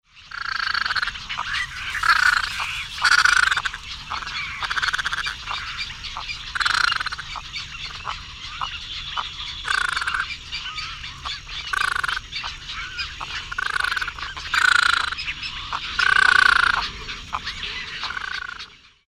Ardeola-ralloides.mp3